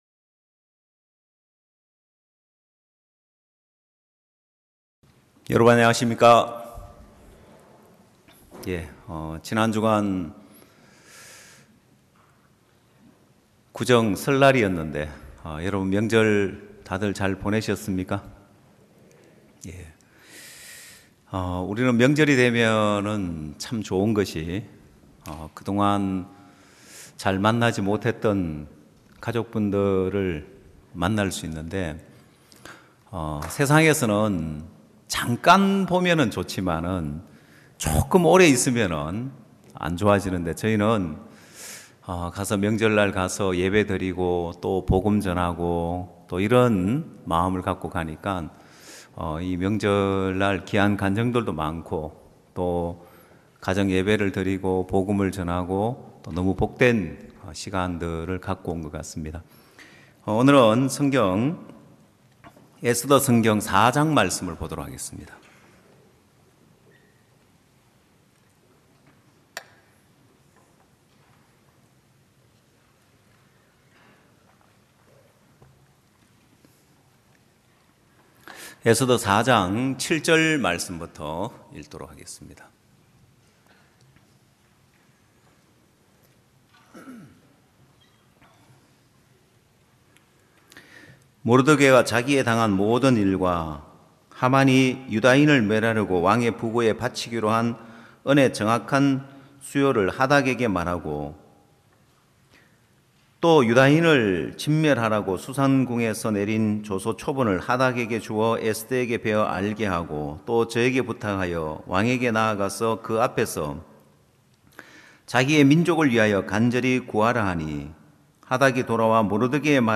성도들이 모두 교회에 모여 말씀을 듣는 주일 예배의 설교는, 한 주간 우리 마음을 채웠던 생각을 내려두고 하나님의 말씀으로 가득 채우는 시간입니다.